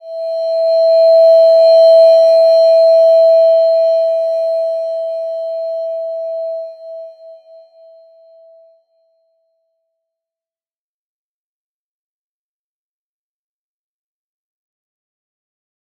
Slow-Distant-Chime-E5-f.wav